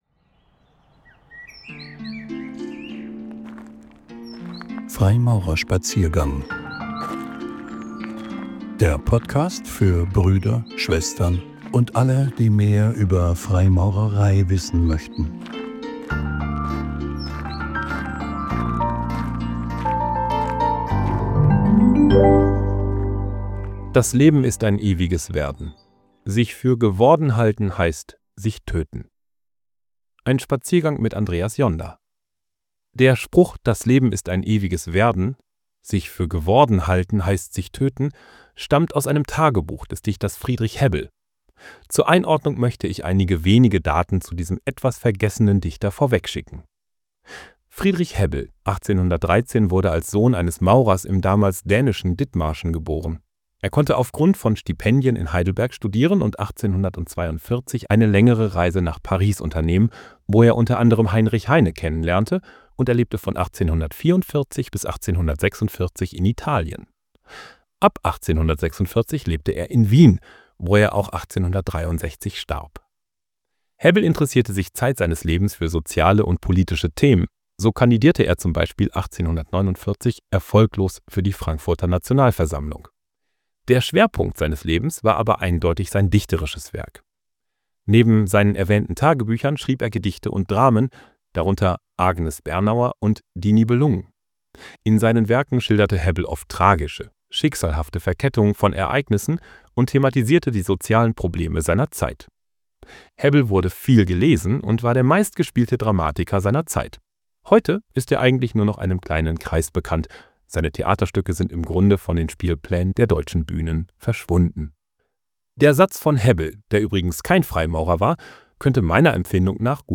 Ausgewählte "Zeichnungen" (Impulsvorträge) von Freimaurern.